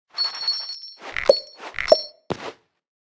Media:Sfx_Anim_Ultra_Trader.wav 动作音效 anim 在广场点击初级、经典、高手和顶尖形态或者查看其技能时触发动作的音效
Sfx_Anim_Ultra_Trader.wav